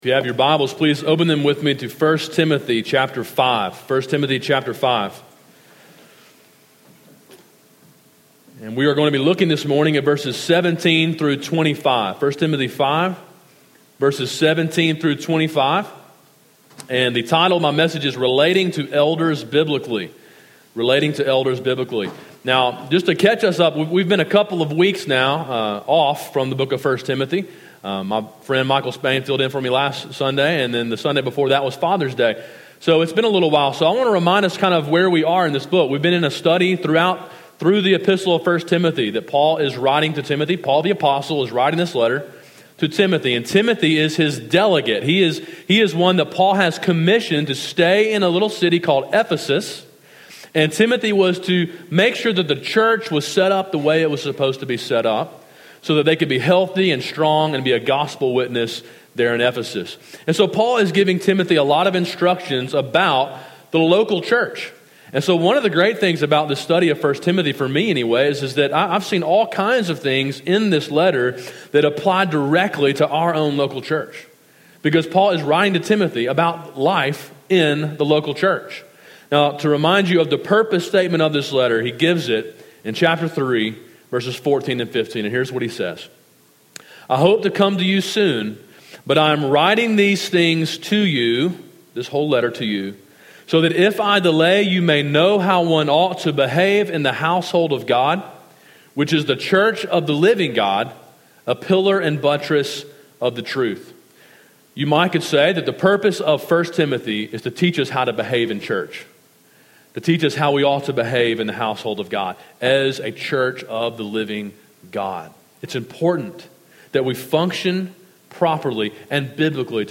A sermon in a series on 1 Timothy.